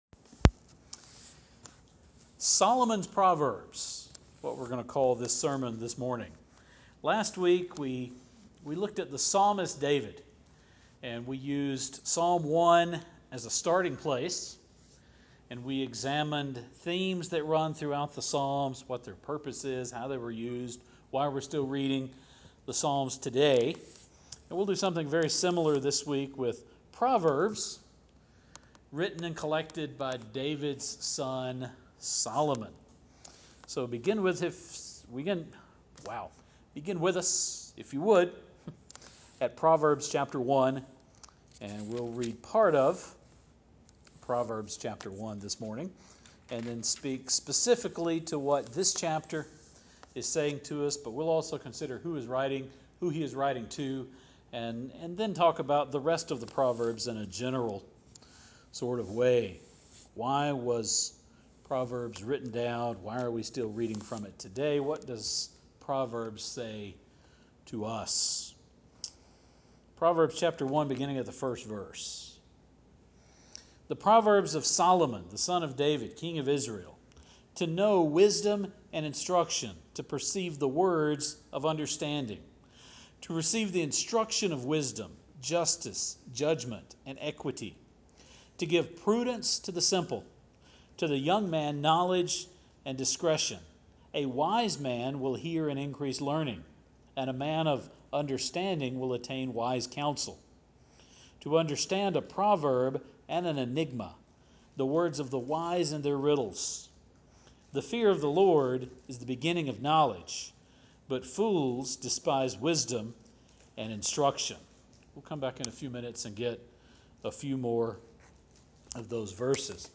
*At some point I mashed the words rebuke and reproof together and got “rebufe.” I didn’t hear until listening to the sermon audio myself Monday afternoon. 2 Timothy 3:16 in the NRSV should read like this: All Scripture is given by inspiration of God, and is profitable for doctrine, for reproof, for correction, for instruction in righteousness,